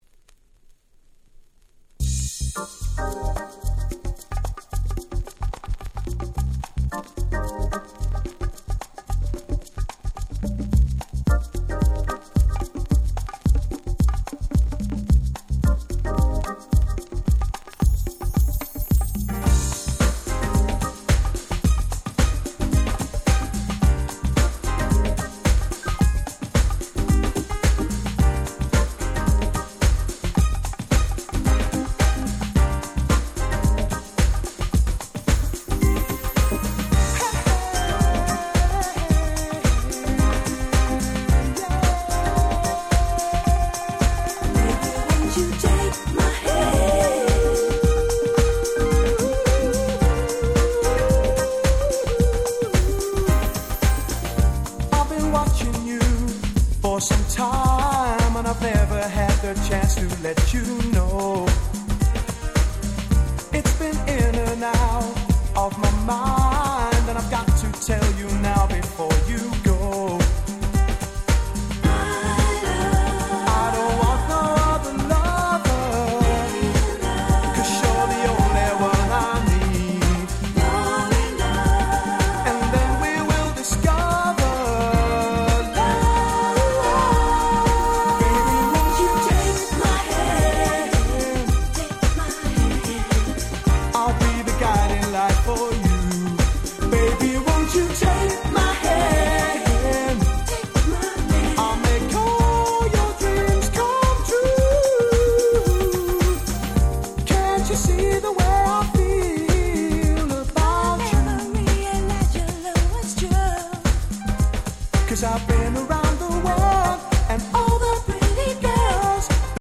【Media】Vinyl 12'' Single
※一部試聴ファイルは別の盤から録音してございます。
91' Nice UK Soul !!